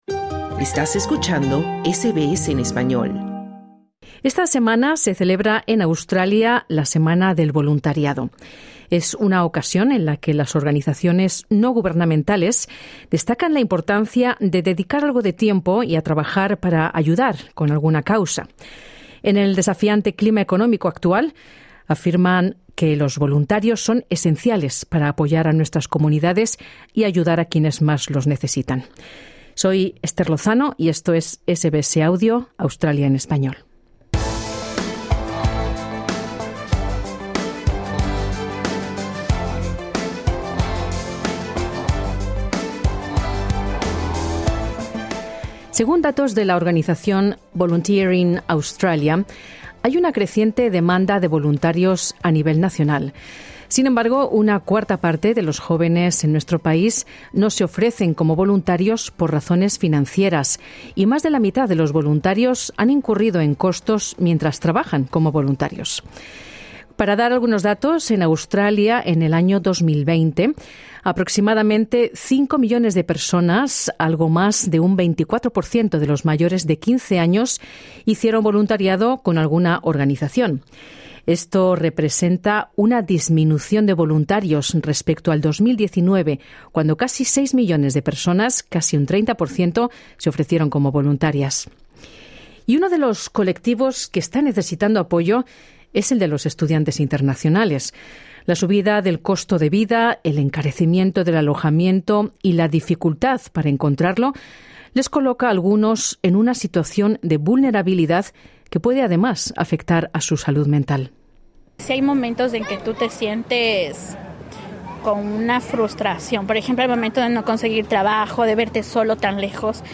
Tras el suicidio el pasado año en Sídney de tres estudiantes latinoamericanos, la comunidad de la iglesia de St Michael en Hurstville, Sídney, ha puesto en marcha un programa gratuito para apoyar a los recién llegados en medio de las dificultades por el aumento del costo de la vida y la dificultad para encontrar vivienda. Estudiantes recién llegados y voluntarios ofrecen a SBS Spanish sus testimonios sobre esta realidad.